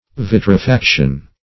Search Result for " vitrifaction" : The Collaborative International Dictionary of English v.0.48: Vitrifaction \Vit`ri*fac"tion\, n. [Cf. Vitrification .] The act, art, or process of vitrifying; also, the state of being vitrified.
vitrifaction.mp3